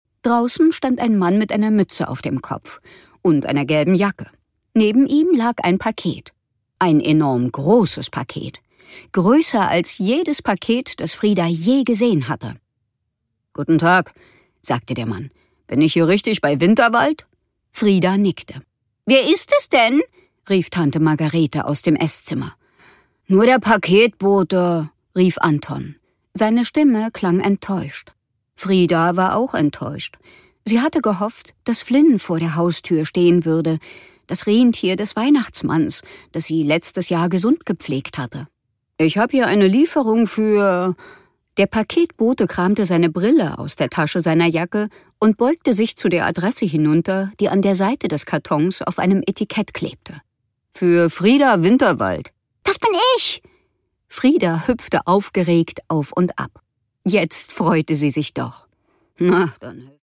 Produkttyp: Hörbuch-Download
Ihre klugen und lebhaften Interpretationen und ihre große Stimmenvielfalt machen jedes ihrer Hörbücher zu einem Erlebnis.